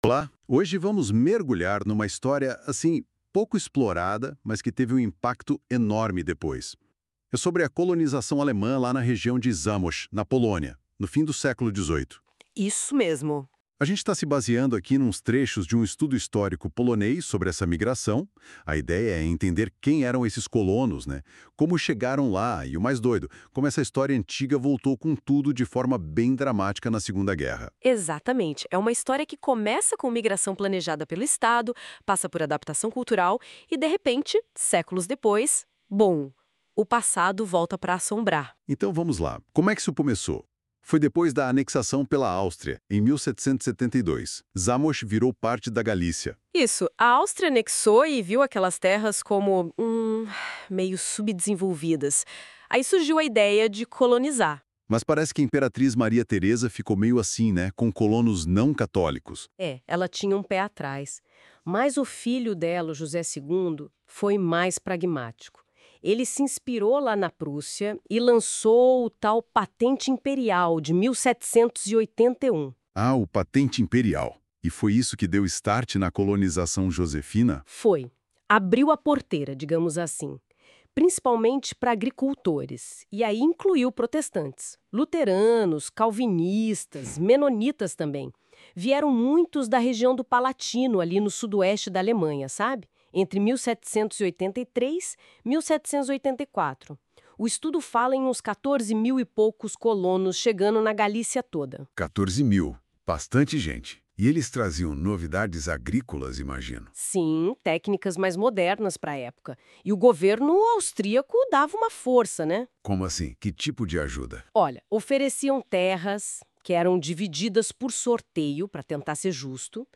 Resumo criado com NotebookLM Pro (Google) on 04.06.2025
• Resumo em áudio (podcast) em português.